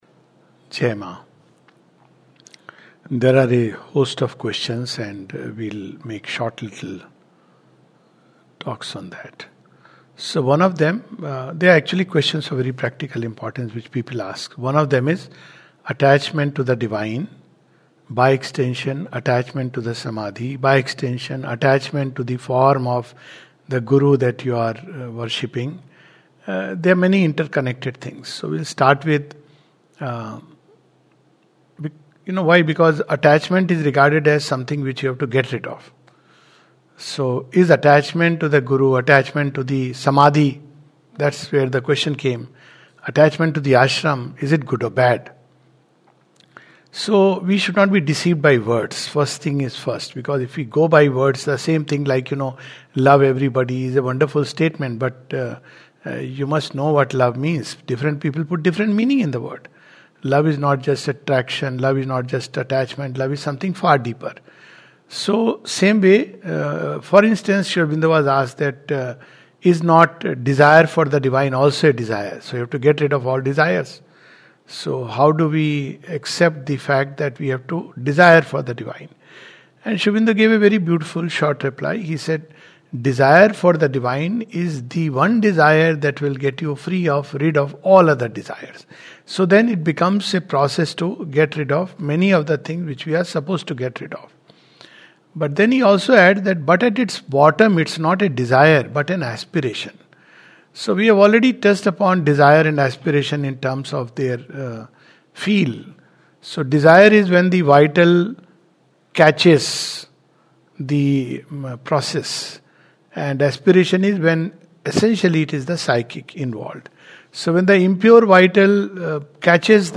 This reflection touches upon the issue of attachment to the Guru and what it means and implies for a disciple. A talk